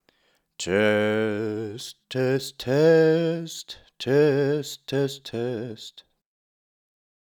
used a Mélodium 75A microphone for that vintage Django tone